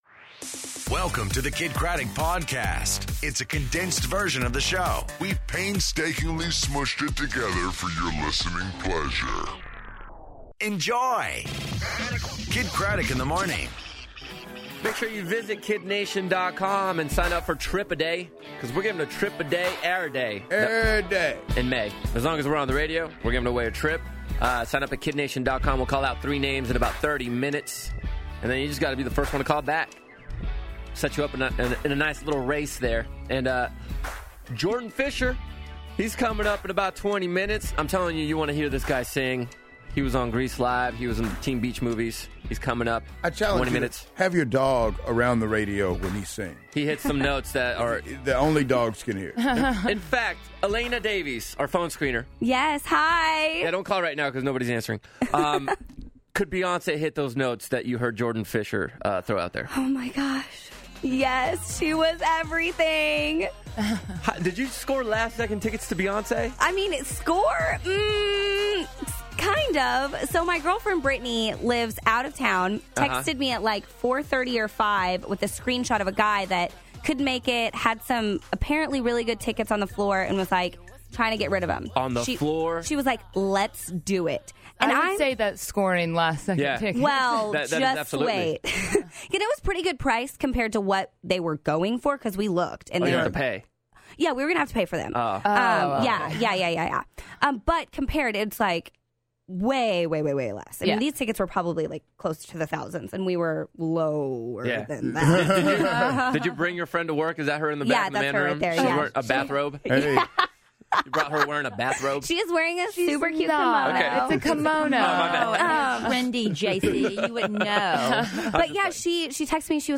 Jordan Fisher In Studio